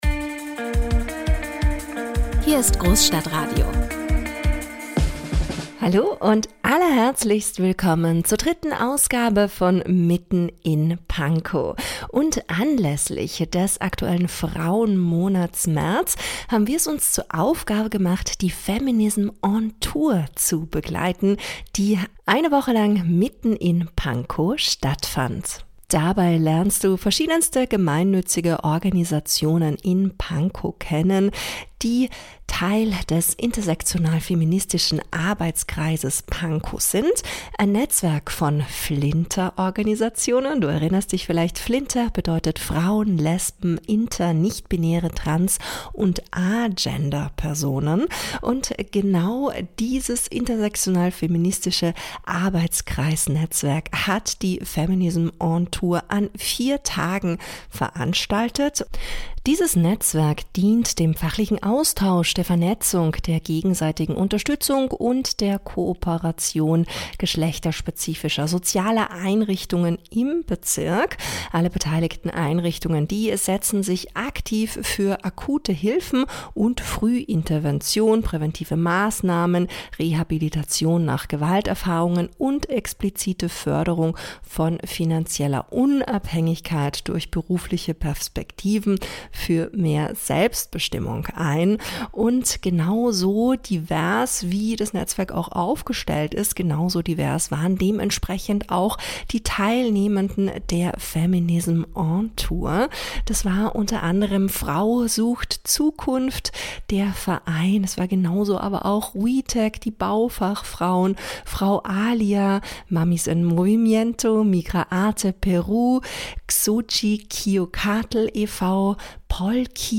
ab 02.46 Min.: Interview mit Dr. Cordelia Koch (Bündnis 90/Die Grünen), Bezirksbürgermeisterin von Pankow, zum Start der Tour